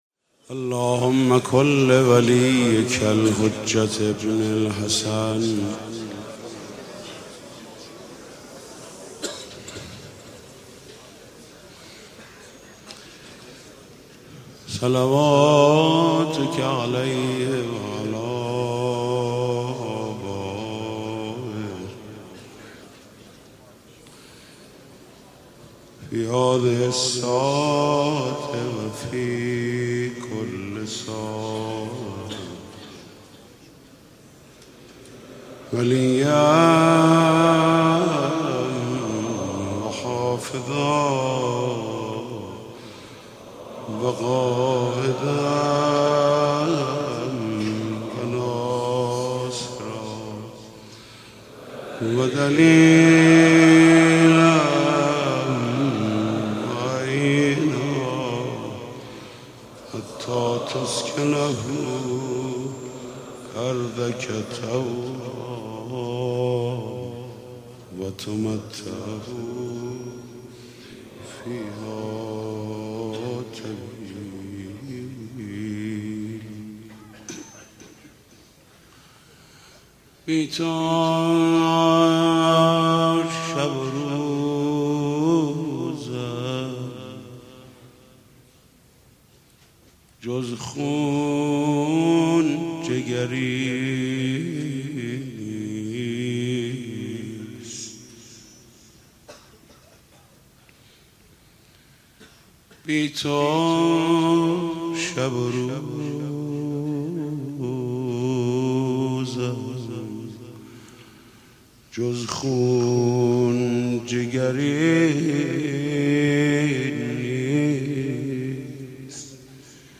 شلوغ شده دور و برش قاتل اومد (شور زیبا)
مجموعه مراسم محمود کریمی در شب نهم محرم 93